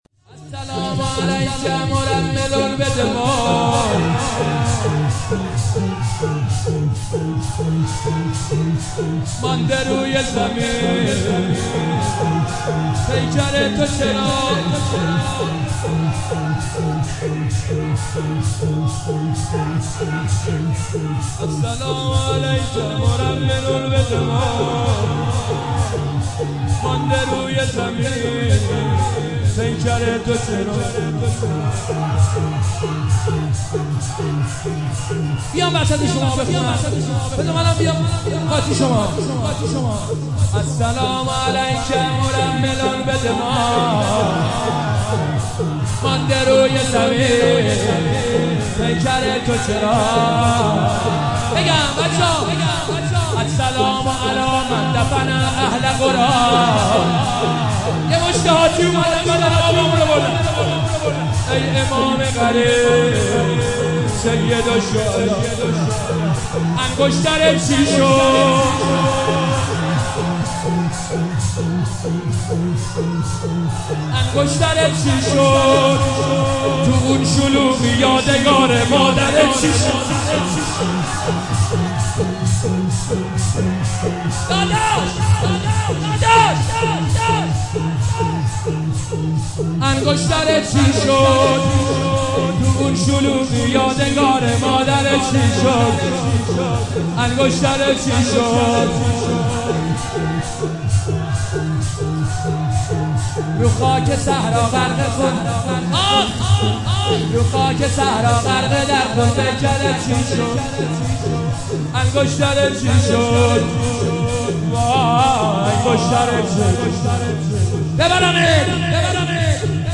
مداحی جدید حاج حسین سیب سرخی شب اول محرم97 هیئت روضة‌ العباس